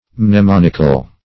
mnemonical - definition of mnemonical - synonyms, pronunciation, spelling from Free Dictionary
Mnemonical \Mne*mon"ic*al\ (n[-e]*m[o^]n"[i^]*kal)